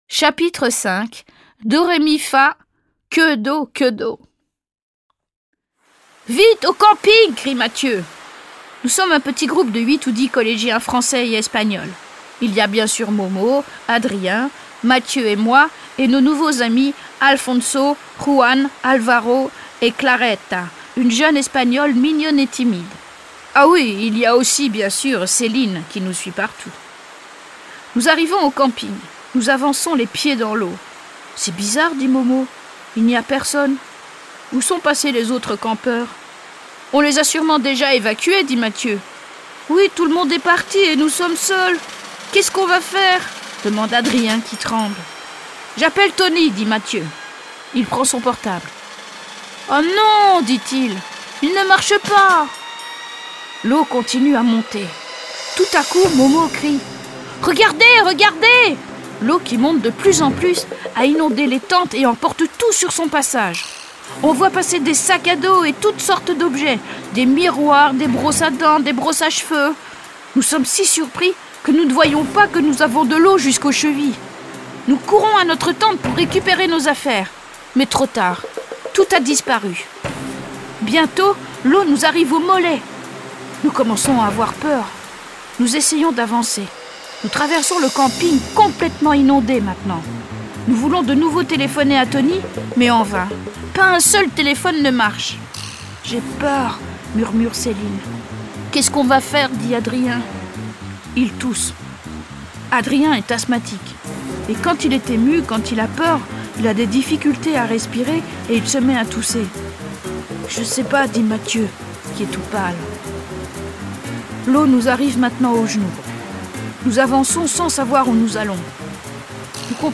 Lecture en français langue étrangère (FLE) dans la collection Découverte destinée aux adolescents niveau A2.